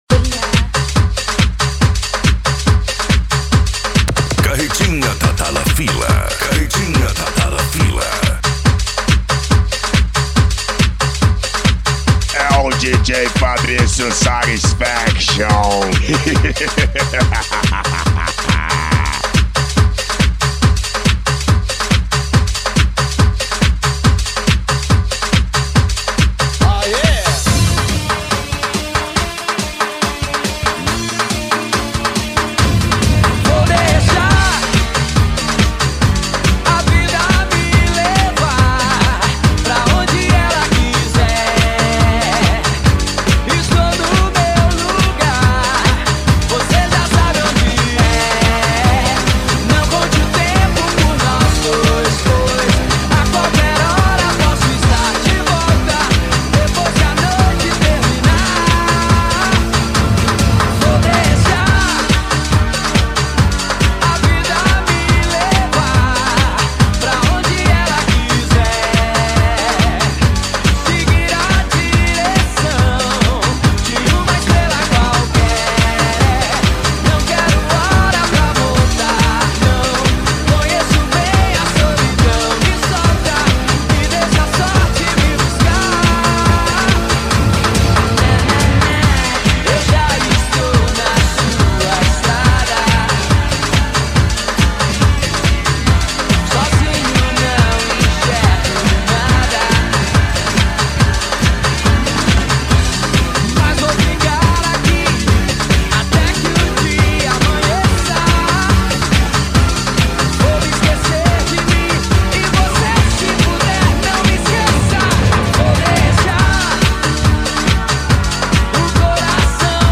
PANCADÃO
Retro Music
SERTANEJO